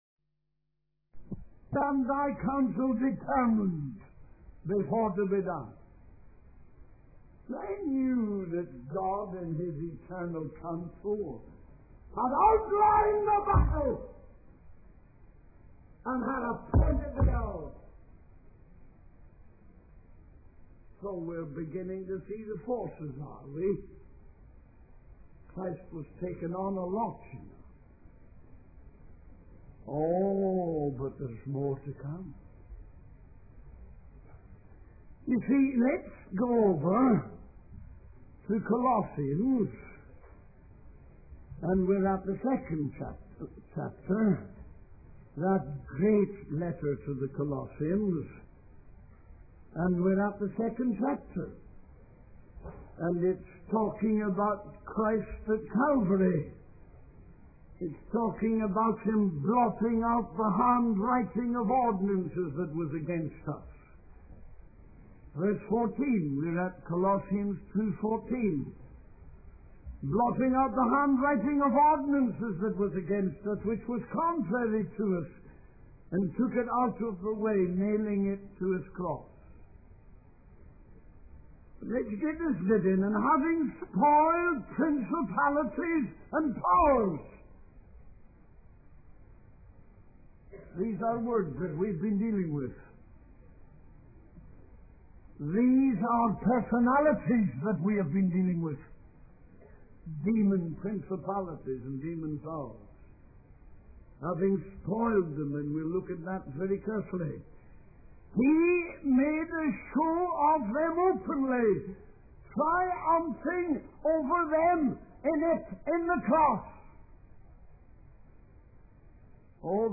In this sermon, the preacher emphasizes the importance of understanding and engaging in spiritual warfare against the devil and his dark forces.